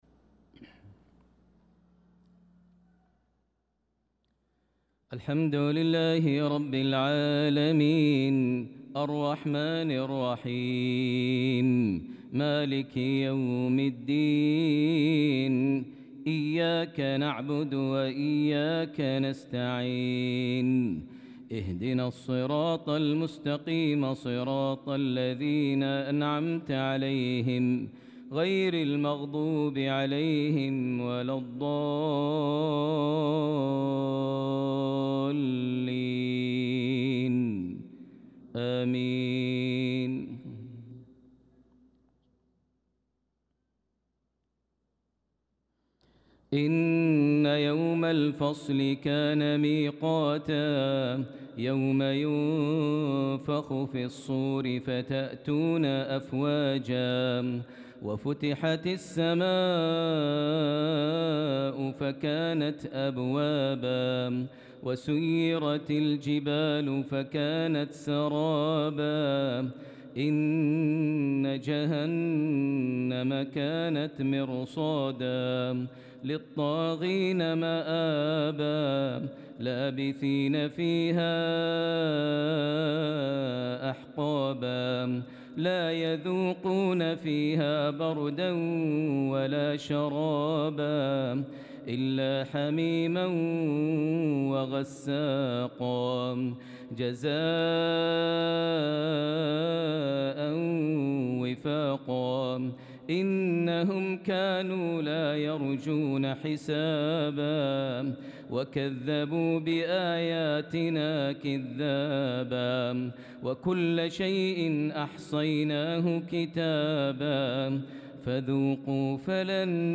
صلاة العشاء للقارئ ماهر المعيقلي 23 ذو القعدة 1445 هـ
تِلَاوَات الْحَرَمَيْن .